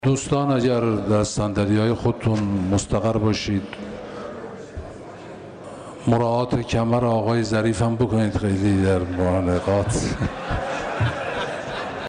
رئیس مجلس شورای اسلامی در ابتدای جلسه علنی امروز اولین سخن خود را با وزیر امور خارجه با مزاح شروع کرد.